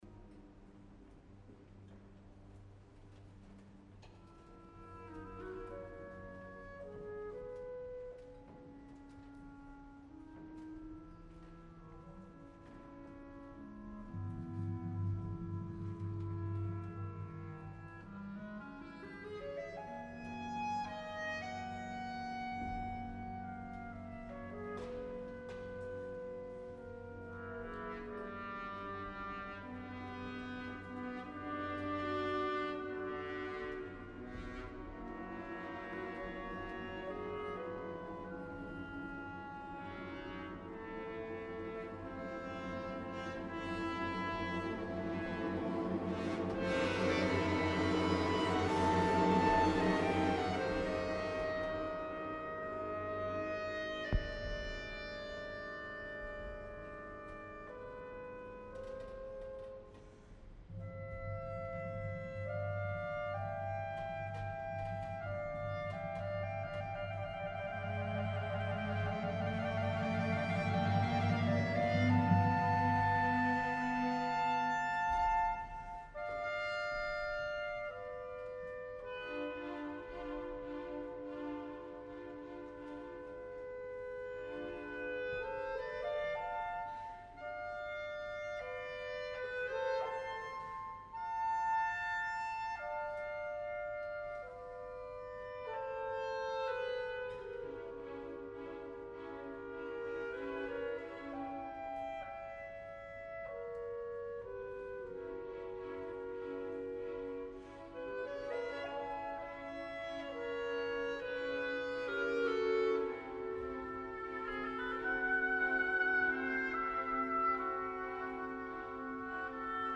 Ja hi hauria d’estar acostumat, fa molts anys que els desenganys bayreuthians és succeeixen en un espiral descendent que sembla que no tingui fi. però potser l’edició del 2014 la recordarem per la mediocritat d’unes veus ínfimes que no poden sostenir amb la mínima dignitat rols que els superen per vocalitat, capacitats i tècnica.
De les quatre òperes que conformen el Ring crec que a Petrenko la que li ha costat més ha estat aquesta darrera, potser perquè el cast massa sovint feia aigües o perquè l’orquestra no va estar tan concentrada com en les dues jornades primeres, però ha passat una mica com l’any passat, i al final tot i que en conjunt m’ha agradat molt, hi han hagut errades, que tampoc és el que més amb molesta, ja que són accidents com tantes vegades hem comentat, però en canvi moments tan emblemàtics com el viatge per el Rhin que inicia el primer acte, que em va semblar massa precipitat , com la mateixa música